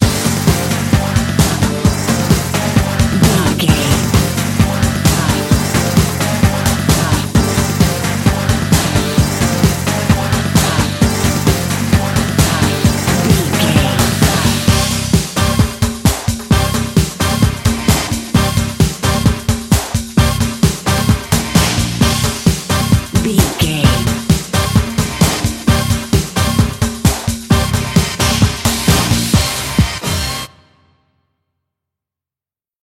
Fast paced
Ionian/Major
Fast
synthesiser
drum machine